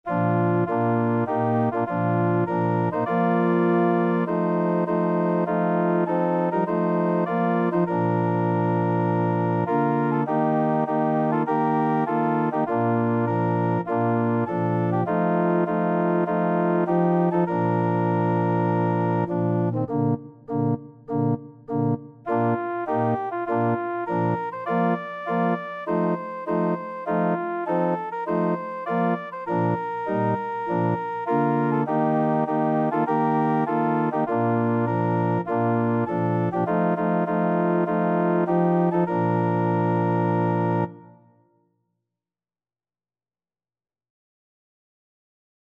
Free Sheet music for Organ
4/4 (View more 4/4 Music)
Bb major (Sounding Pitch) (View more Bb major Music for Organ )
Organ  (View more Intermediate Organ Music)
Christian (View more Christian Organ Music)
church_in_the_wildwood_ORG.mp3